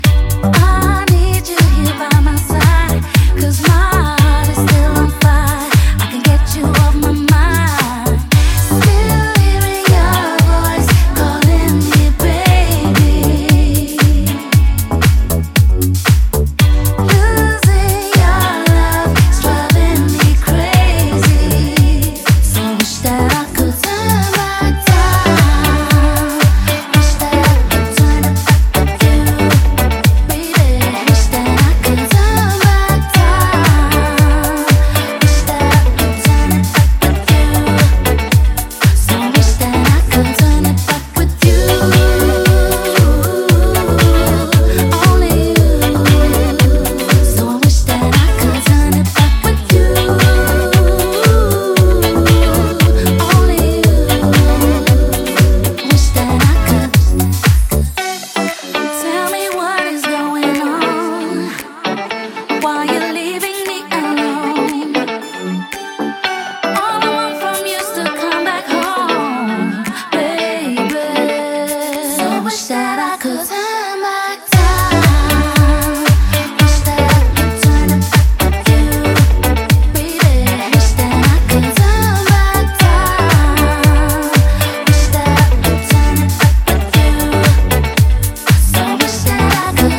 (B面)は各曲ともにソウルフル＆ディスコ〜ディープ・ハウスで捨曲一切無しです。
ジャンル(スタイル) DISCO / HOUSE